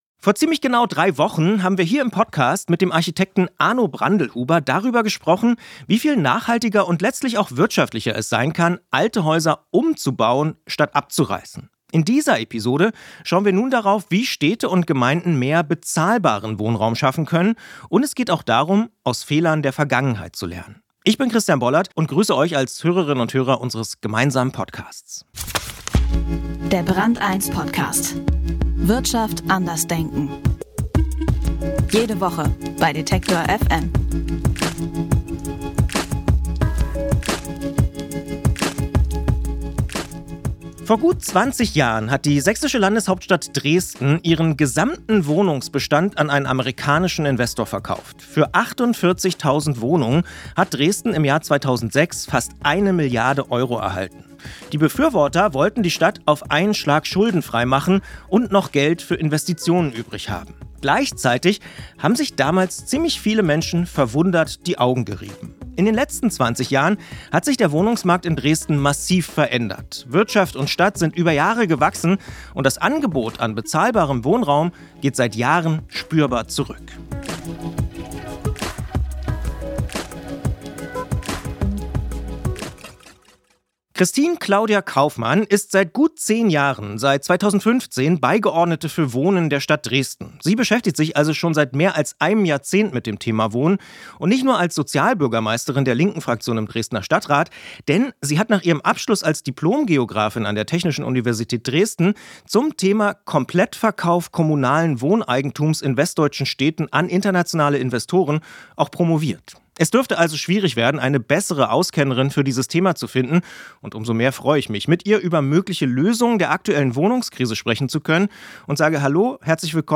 Wie können Städte und Gemeinden mehr bezahlbaren Wohnraum schaffen? Kristin Klaudia Kaufmann im Gespräch.